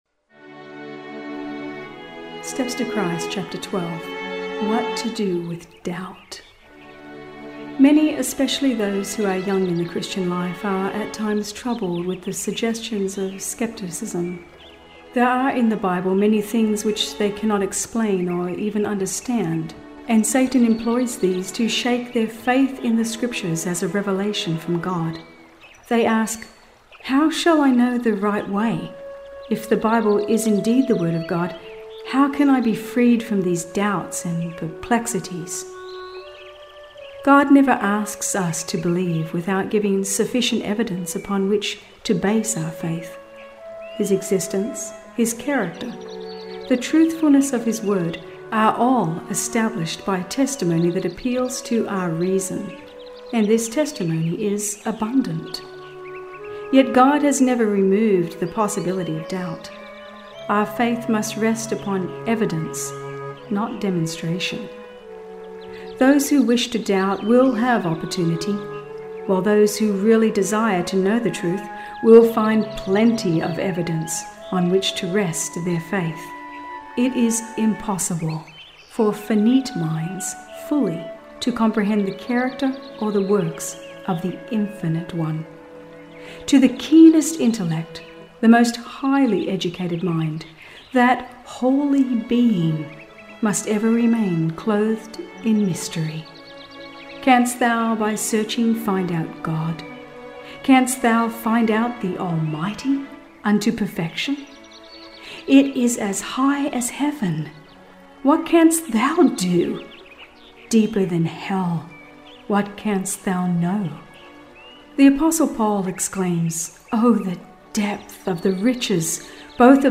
12 - What to Do with Doubt - Dramatized Version
Steps-to-Christ-Chapter-12-Myers-Media-With-Music-Score-and-Sound-Effects.mp3